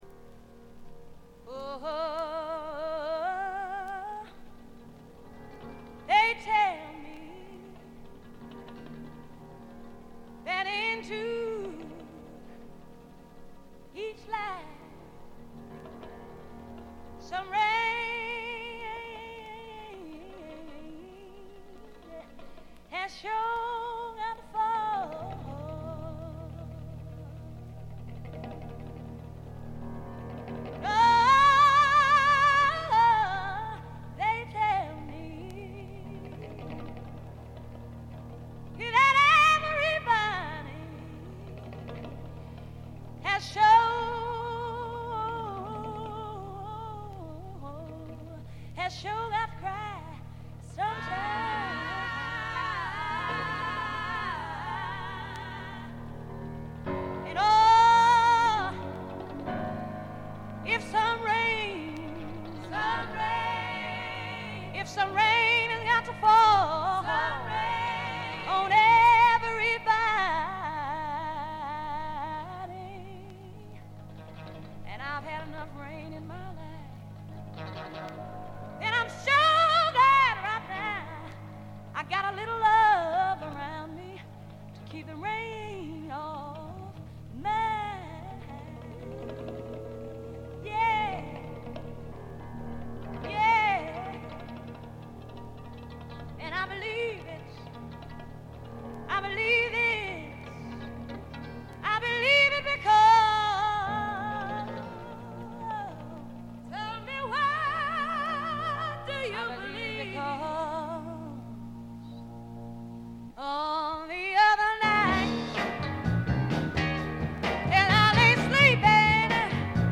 ほとんどノイズ感無し。
試聴曲は現品からの取り込み音源です。
vocals, piano